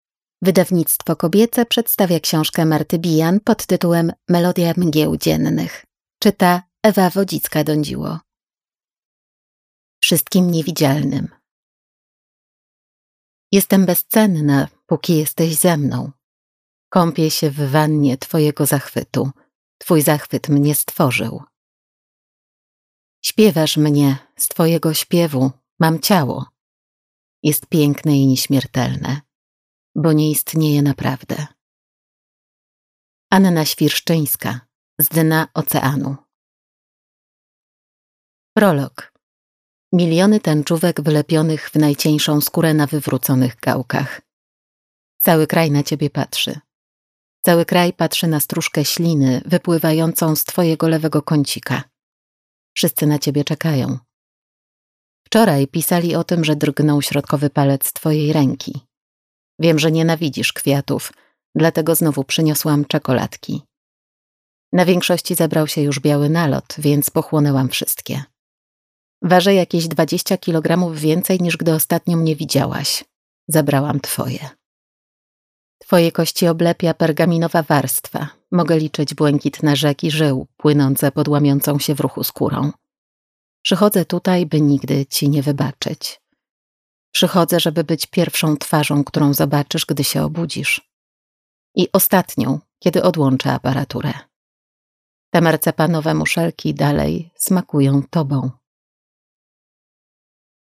Melodia mgieł dziennych - Marta Bijan - audiobook